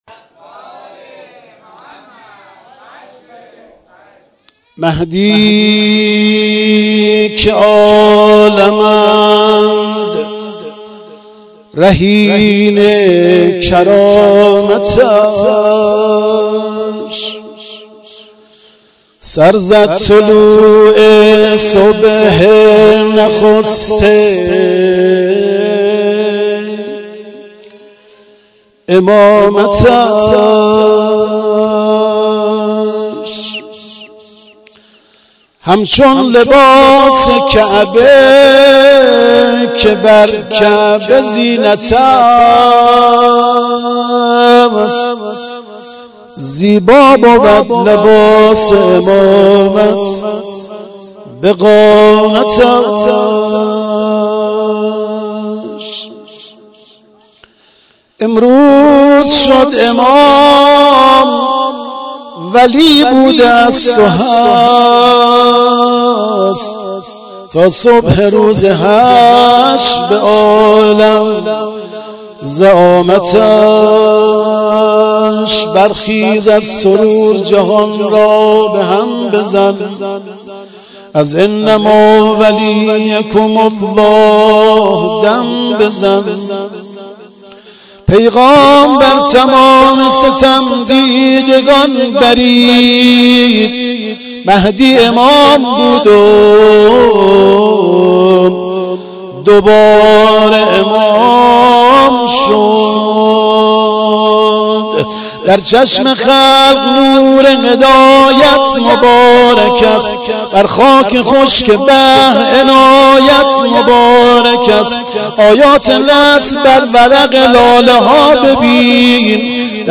مولودی خوانی
مراسم جشن سالروز امامت امام زمان(عج).mp3